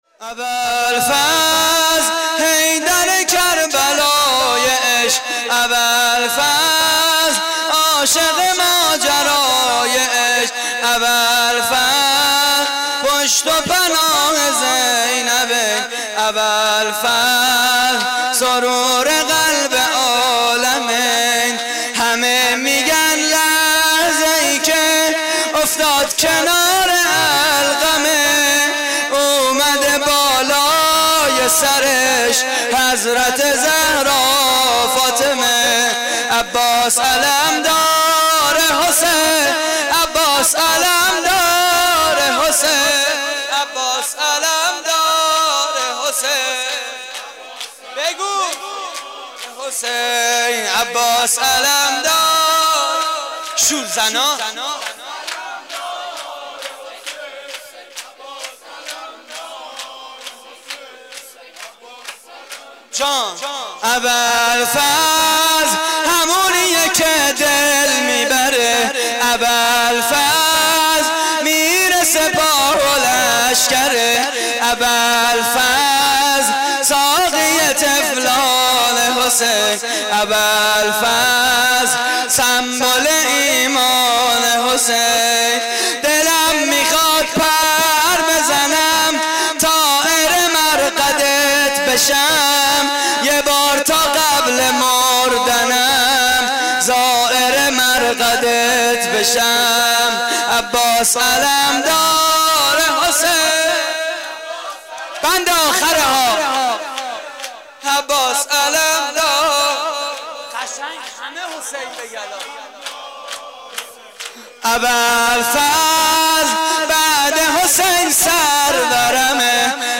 مراسم عزاداری ماه محرم
صوت مراسم:
شور